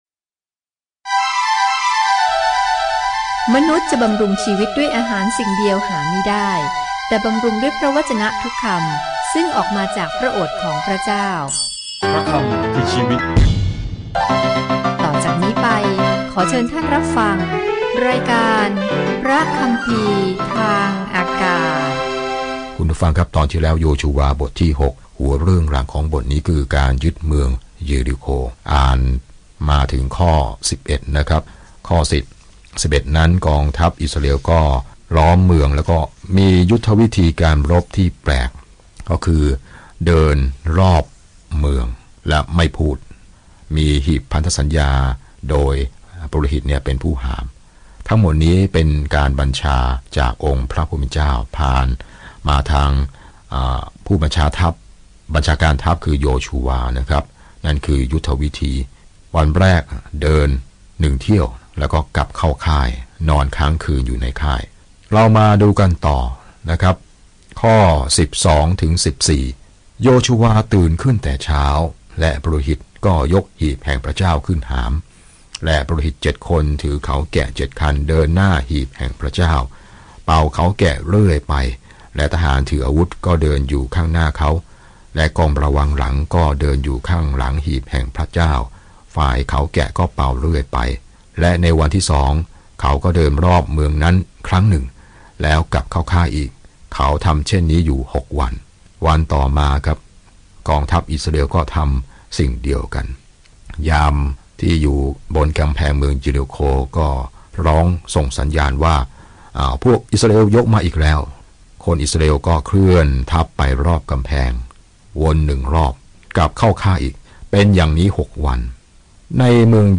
เรามาเรียกหนังสือของโยชูวาว่า "อพยพ: ภาคสอง" เนื่องจากคนรุ่นใหม่ของพระเจ้าเข้ายึดครองดินแดนที่พระองค์ทรงสัญญาไว้ เดินทางทุกวันผ่านโจชัวในขณะที่คุณฟังการศึกษาด้วยเสียงและอ่านข้อที่เลือกจากพระวจนะของพระเจ้า